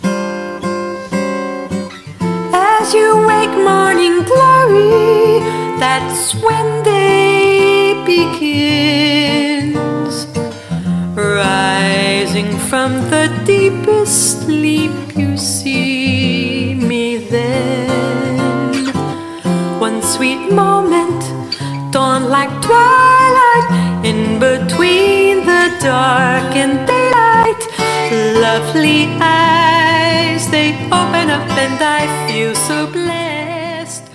recorded this lovely rendition in Austria
guitar